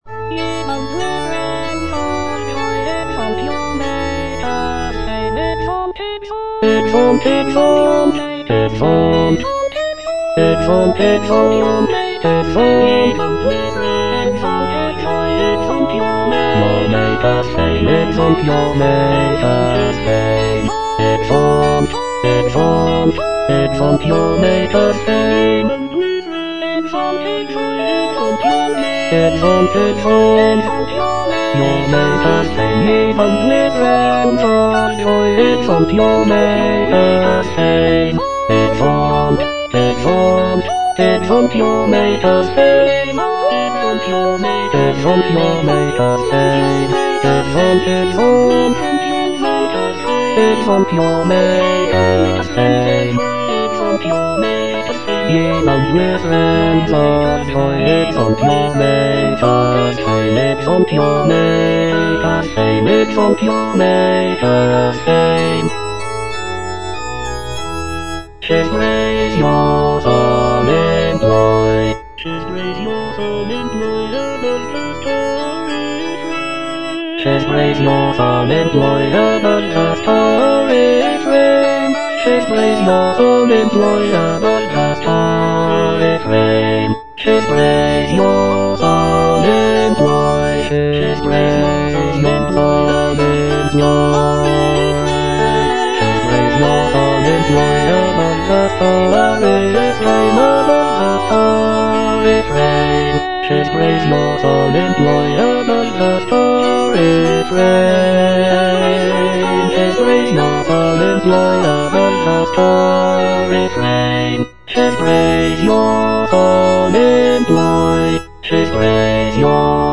(A = 415 Hz)
Bass (Emphasised voice and other voices) Ads stop